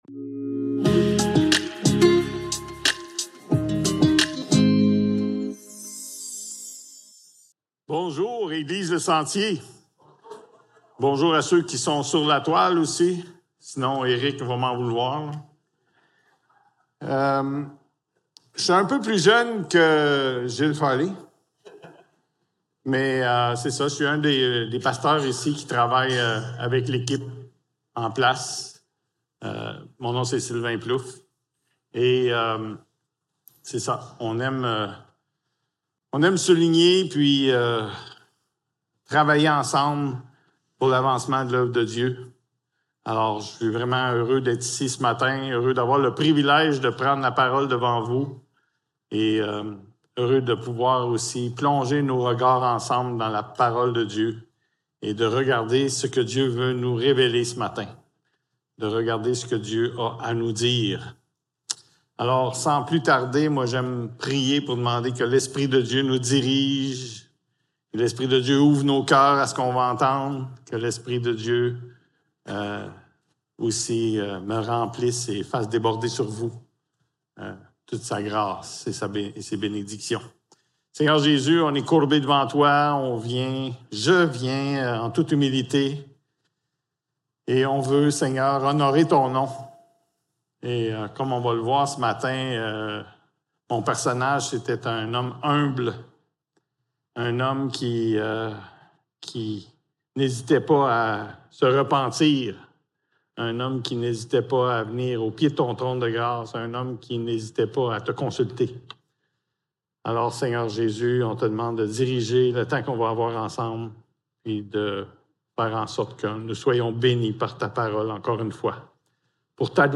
1 Samuel 13.14 Service Type: Célébration dimanche matin Description